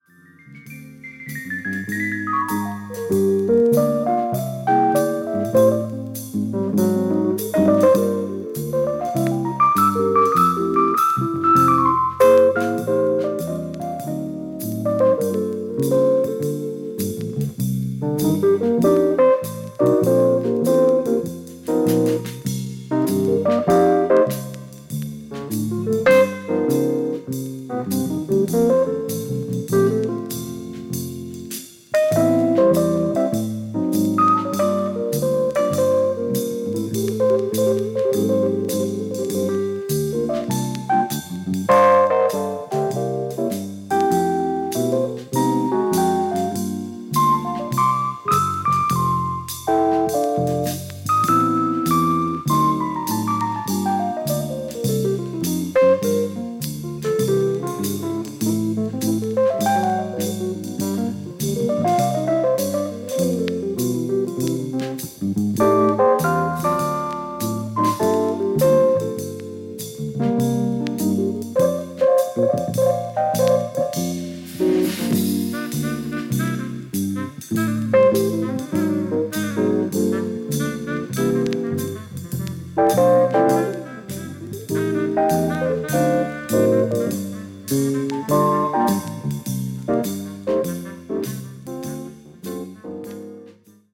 Condition Media : VG-(Side-A・中心ズレ)
Jazz鍵盤奏者